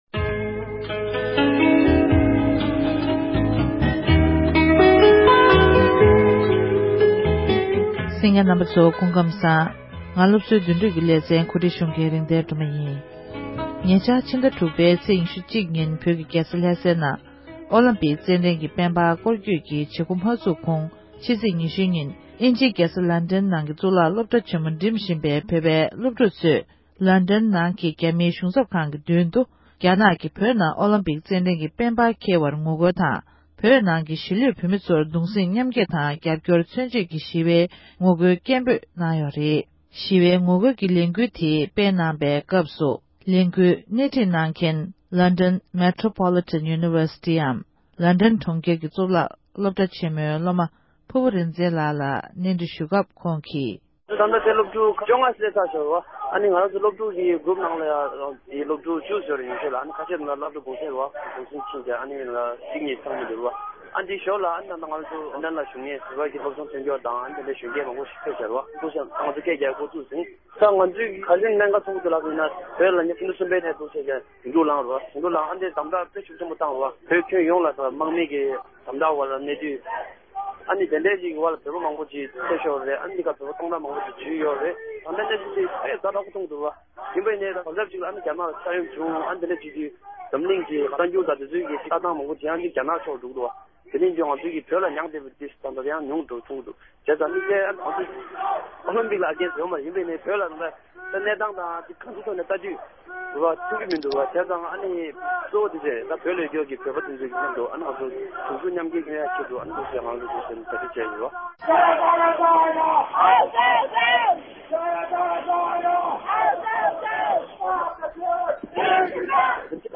འབྲེལ་ཡོད་མི་སྣར་བཅའ་འདྲི་ཞུས་ཏེ་ཕྱོགས་སྒྲིག་ཞུས་པར་གསན་རོགས་གནང༌༎